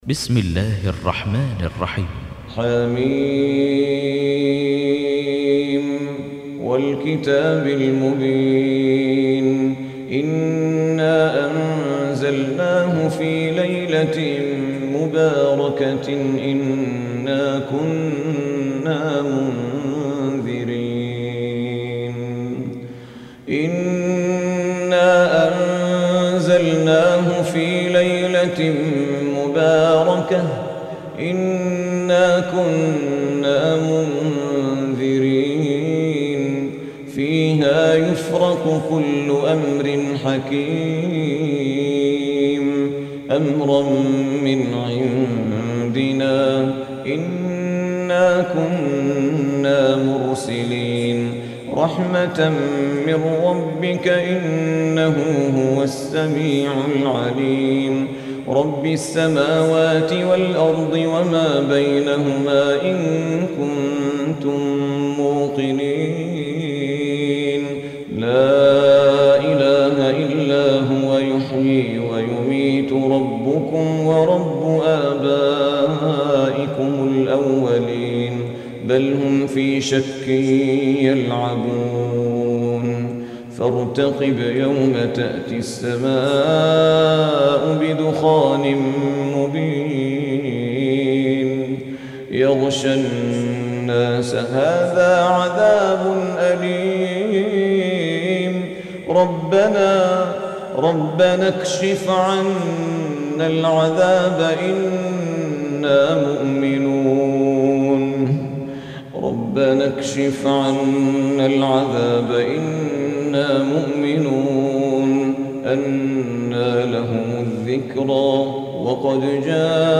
سماع القرآن الكريم